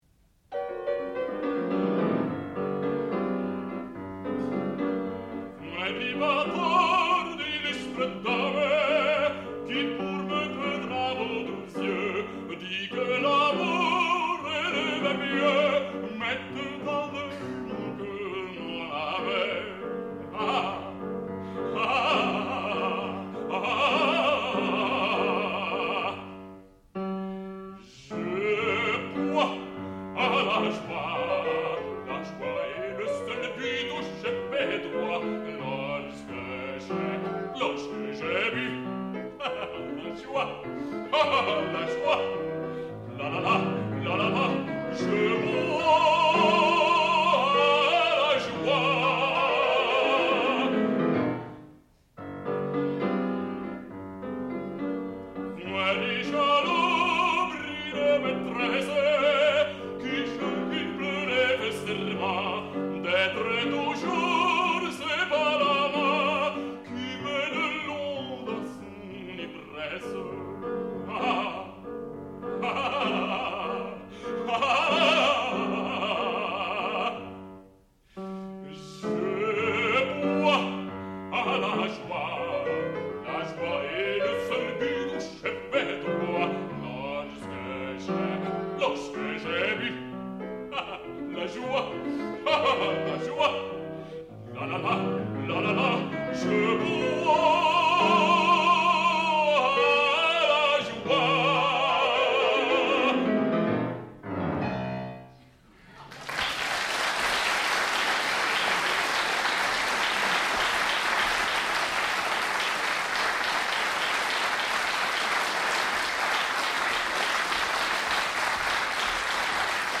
sound recording-musical
classical music
baritone
piano